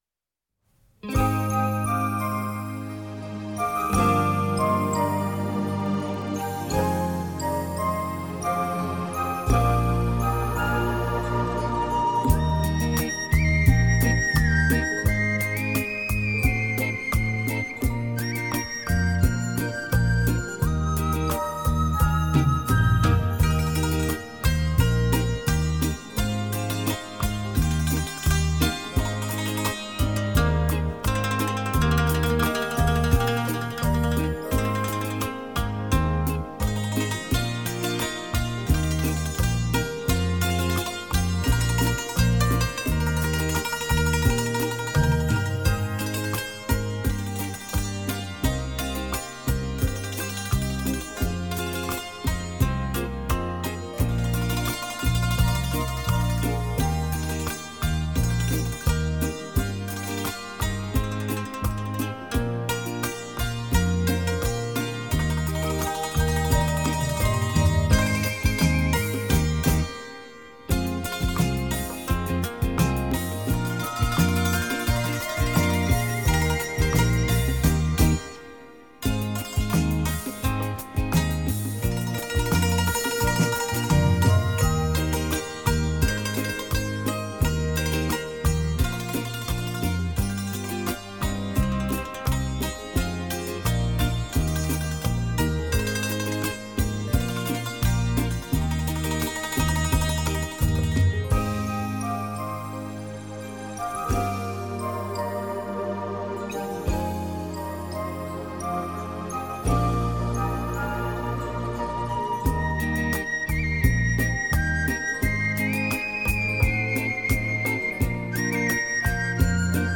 全音域音响测试片